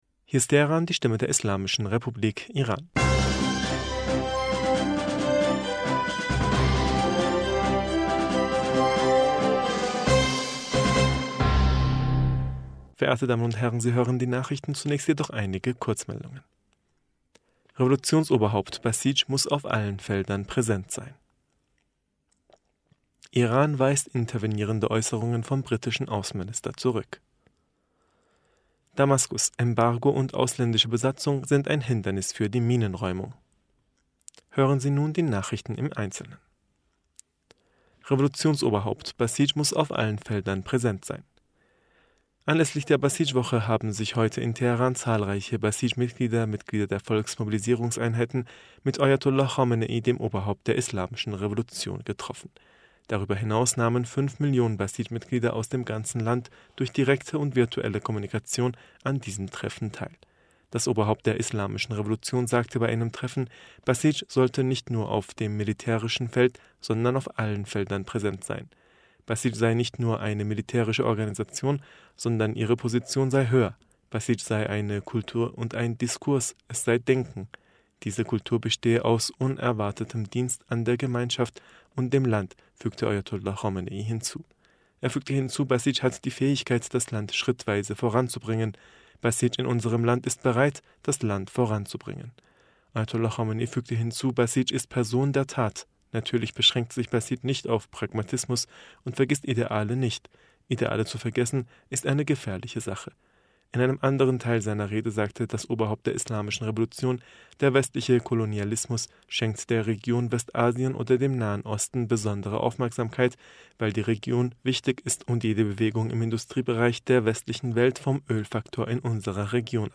Nachrichten vom 26. November 2022